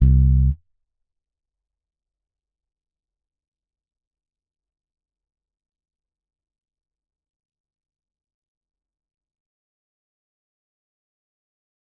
Bass Zion 1.wav